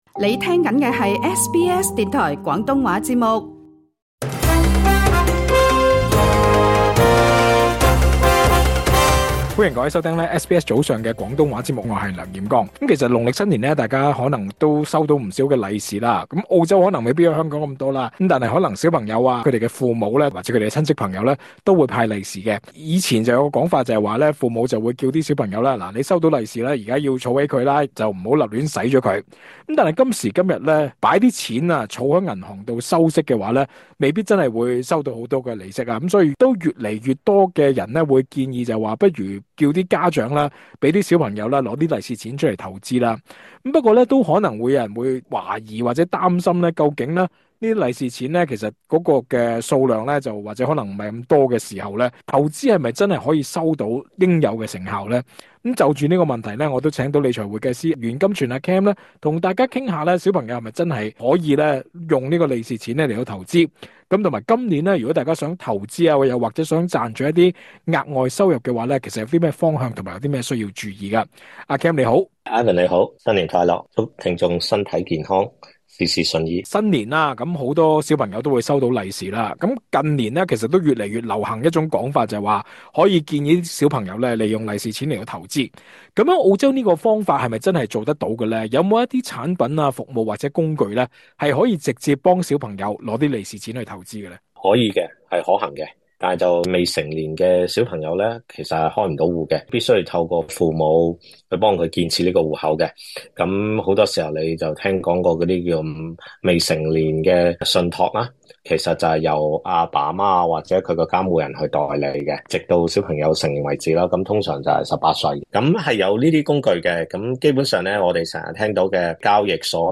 足本訪問： LISTEN TO 【幾多本金先唔會蝕？